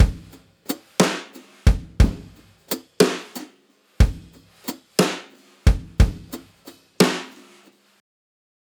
Unison Jazz - 6 - 120bpm.wav